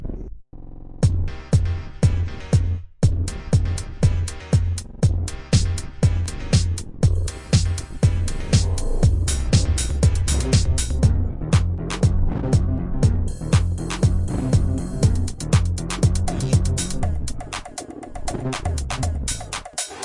口腔竖琴第1卷 " 口腔竖琴10下限音的变化
描述：口琴（通常被称为“犹太人的竖琴”）调到C＃。 用RØDENT2A录制。
Tag: 竖琴 调整Mouthharp 共振峰 仪表 传统 jewsharp 共振峰 弗利